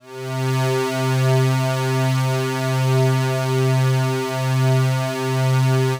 C3_trance_pad_2.wav